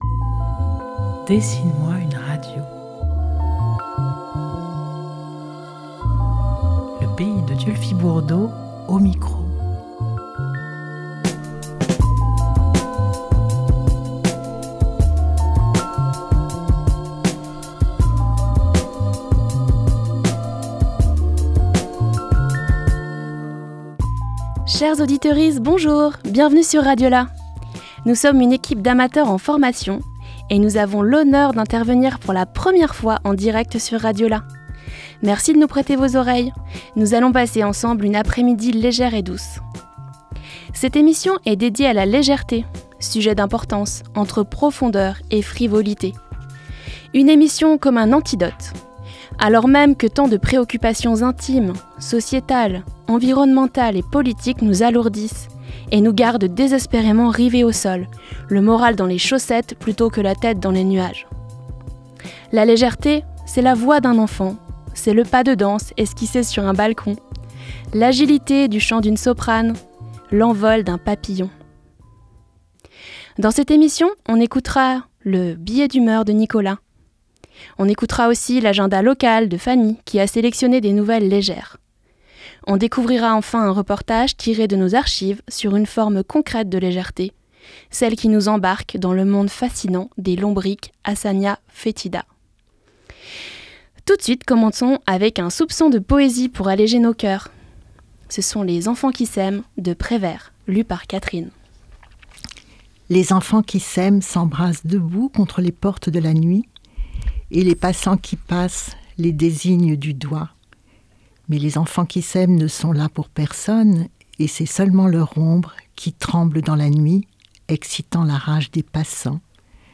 Une émission réalisée par une équipe d’amateurs en formation, qui sont intervenus pour la première fois en direct sur RadioLà afin de passer ensemble une après midi légère et douce !